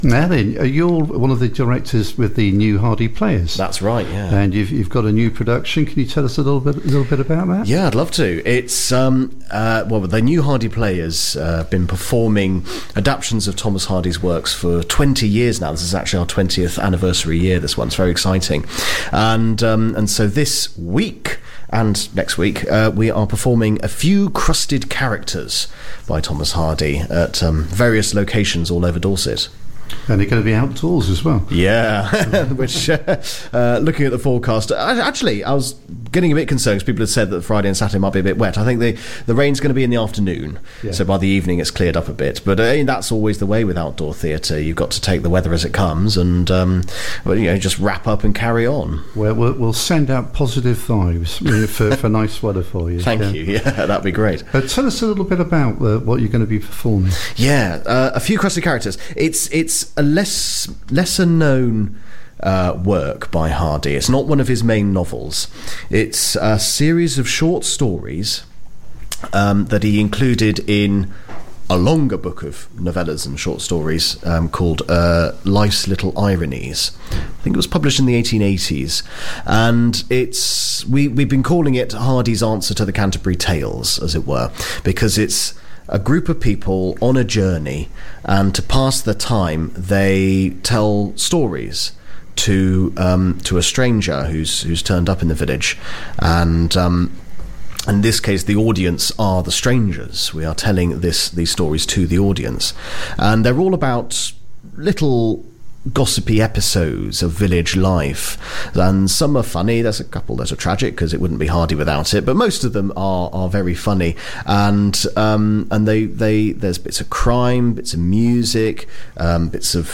The Community Radio Station covering Central-Southern Dorset, run by volunteers and not-for-profit